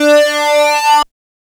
Track 16 - Synth 06.wav